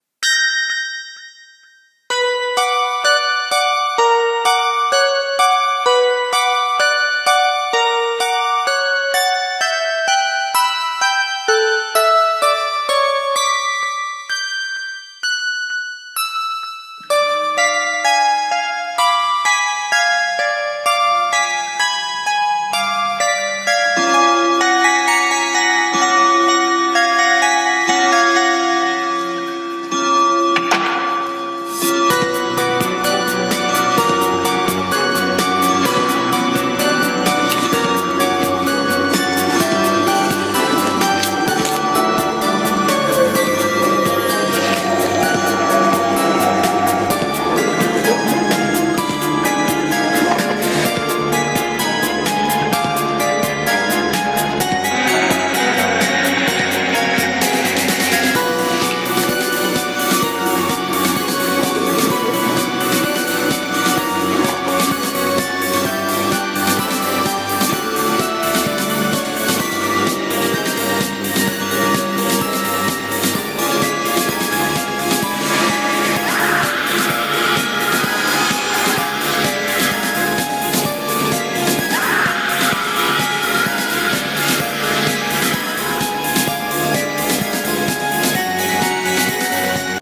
声劇台本1人用「吸血鬼の食事」ドラキュラ版